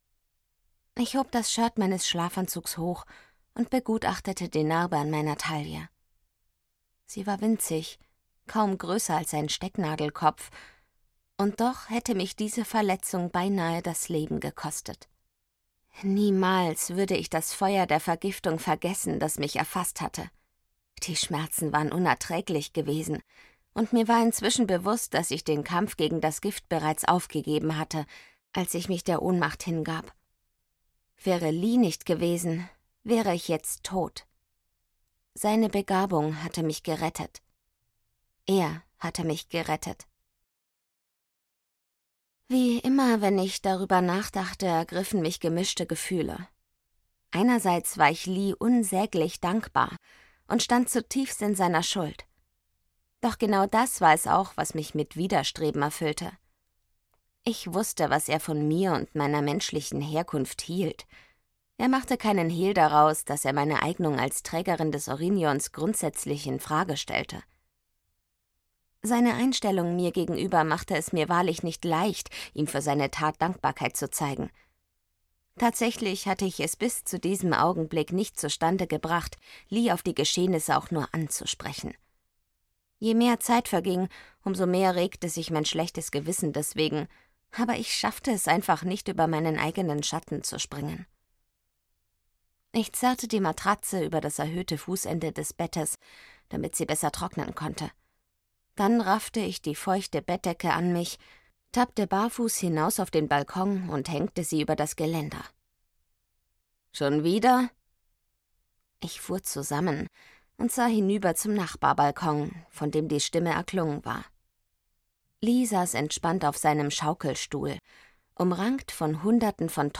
Secret Elements 2: Im Bann der Erde - Johanna Danninger - Hörbuch